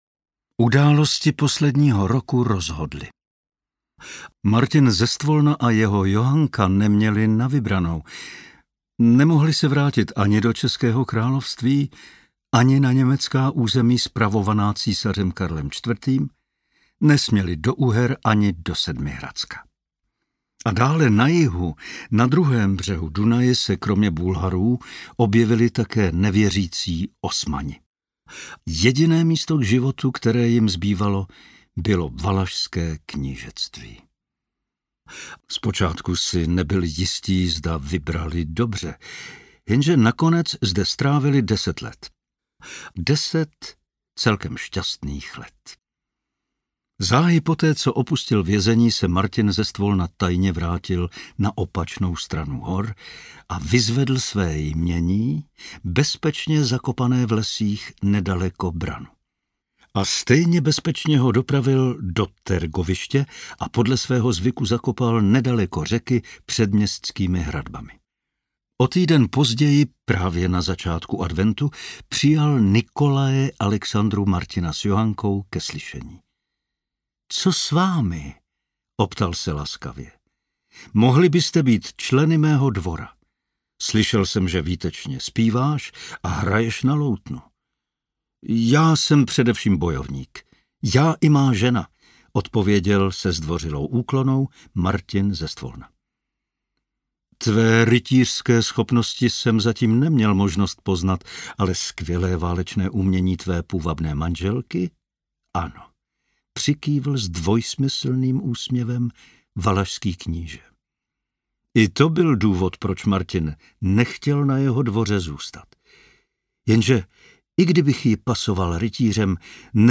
Zloději ostatků II. audiokniha
Ukázka z knihy
• InterpretPavel Soukup